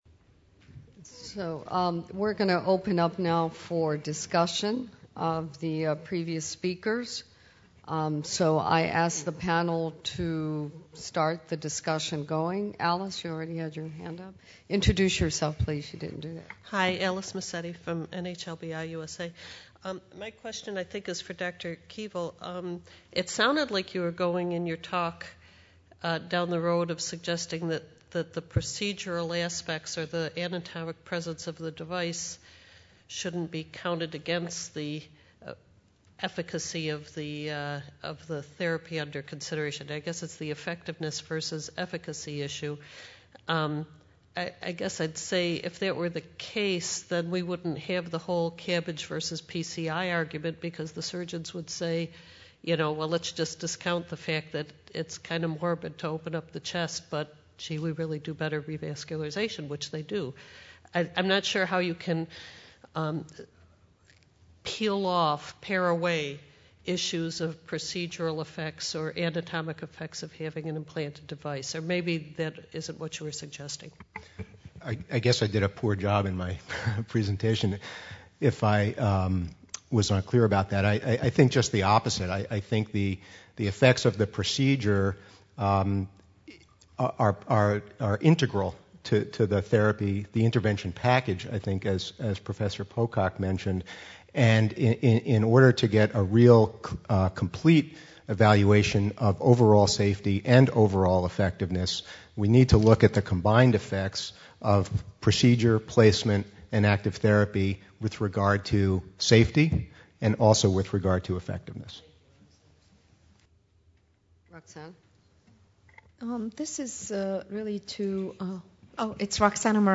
Cardiovascular Clinical Trialists (CVCT) Forum – Paris 2012 - Lunch Session 1 : How much one could deviate from “randomized - controlled” trials? Questions | Canal U